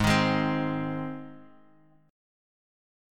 G# Chord
Listen to G# strummed